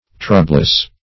Troublous \Trou"blous\, a.